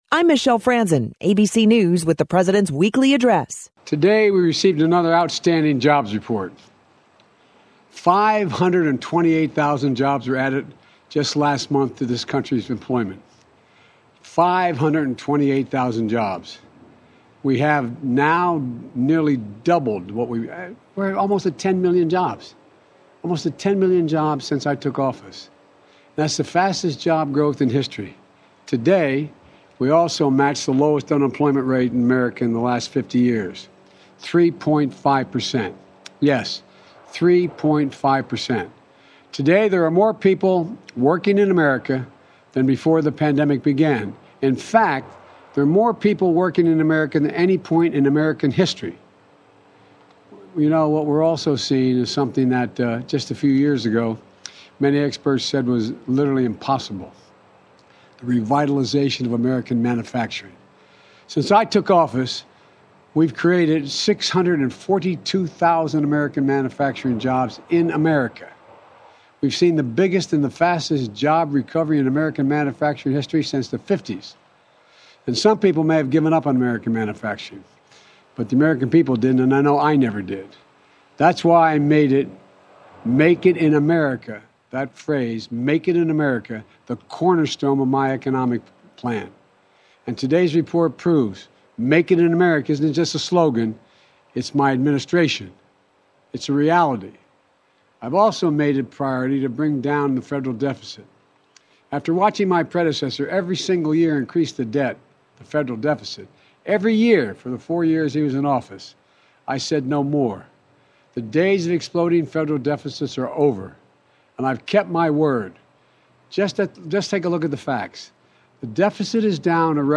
President Biden spoke about the July Jobs Report, that was released last Friday.